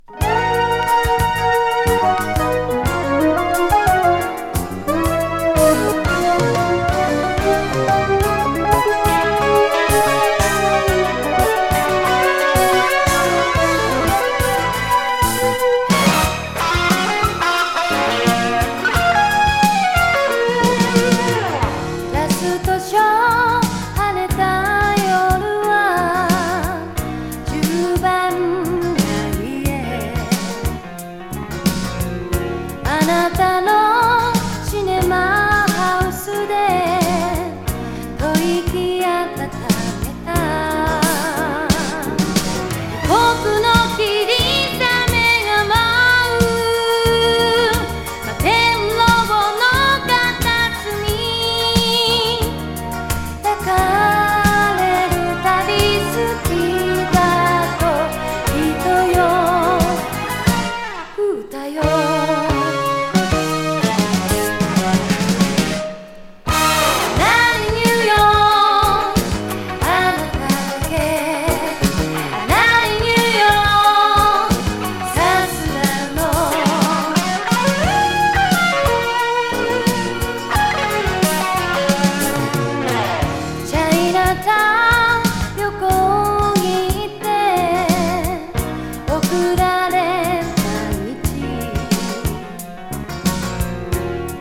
切ないメロディがたまらないアンニュイ歌謡